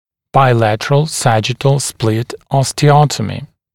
[baɪ’lætərəl ‘sæʤɪtl splɪt ˌɔstɪ’ɔtəmɪ][бай’лэтэрэл ‘сэджитл сплит ˌости’отэми]двусторонняя сагиттальная остеотомия нижней челюсти